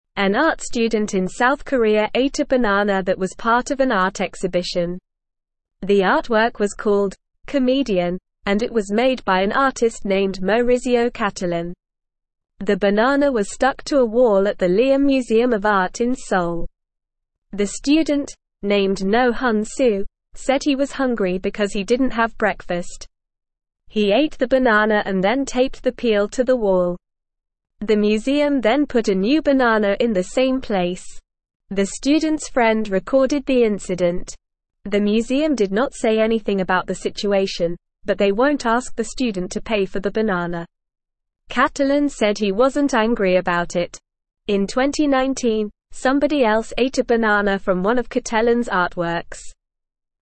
Normal
English-Newsroom-Beginner-NORMAL-Reading-Student-Eats-Museum-Banana-Artwork.mp3